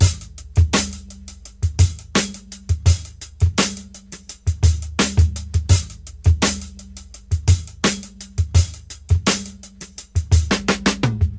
112 Bpm Drum Loop F# Key.wav
Free drum groove - kick tuned to the F# note. Loudest frequency: 3775Hz
112-bpm-drum-loop-f-sharp-key-JF4.ogg